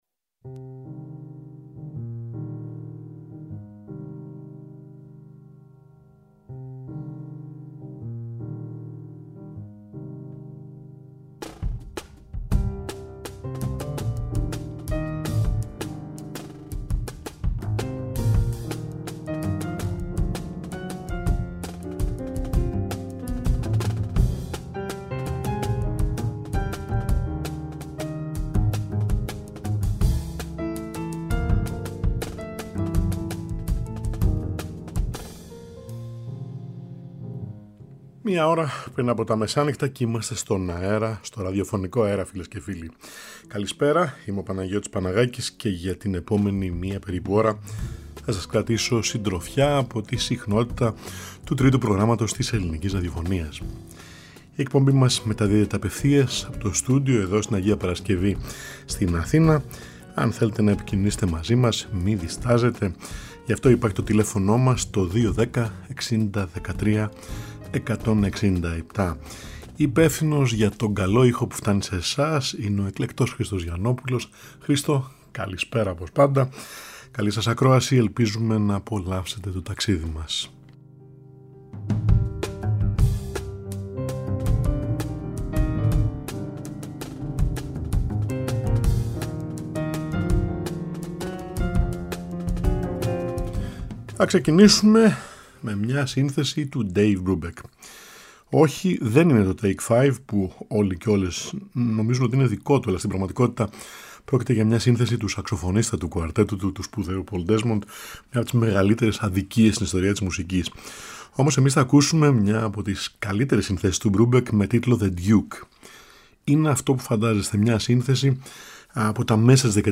Κάθε Τρίτη και Πέμπτη στις έντεκα, ζωντανά στο Τρίτο Πρόγραμμα
Για αυτήν την ώρα που τα φώτα χαμηλώνουν και όλα κυλάνε πιο ήρεμα.